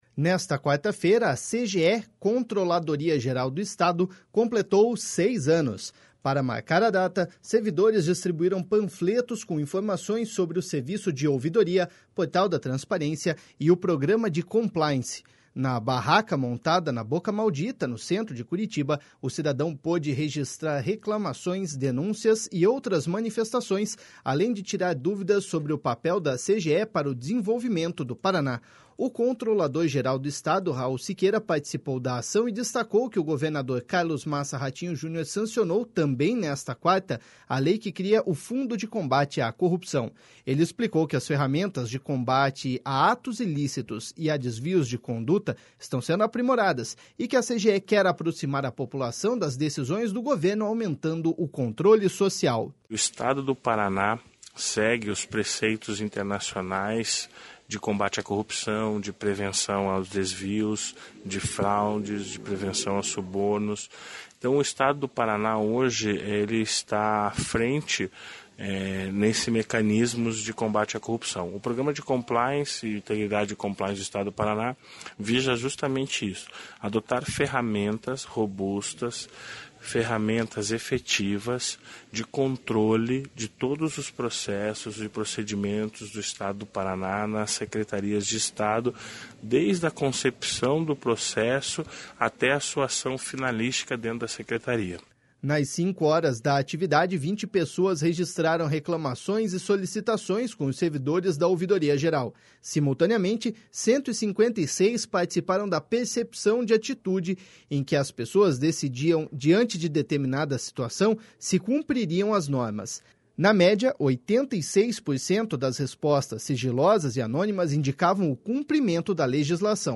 Ele explicou que as ferramentas de combate a atos ilícitos e a desvios de condutas estão sendo aprimoradas, e que a CGE quer aproximar a população das decisões do governo, aumentando o controle social.// SONORA RAUL SIQUEIRA.//